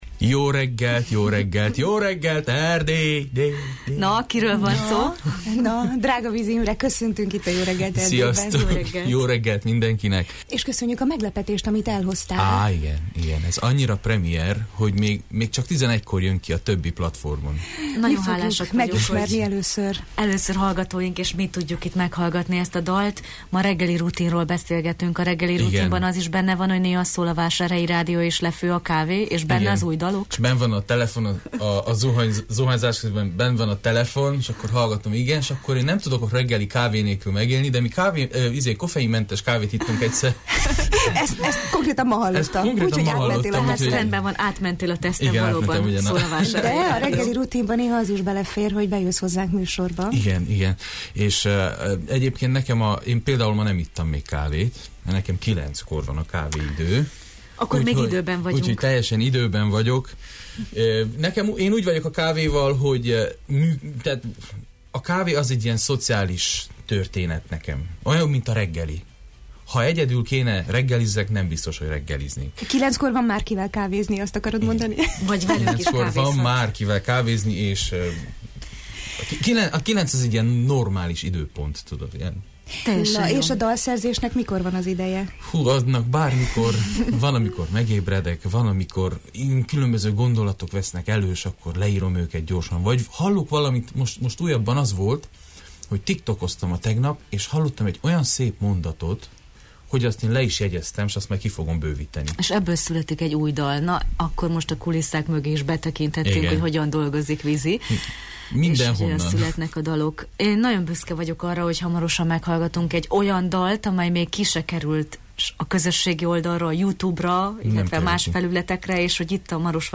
A premier kapcsán dalszerzésről, koncertekről, ihletről, nomeg a legújabb daláról kérdeztük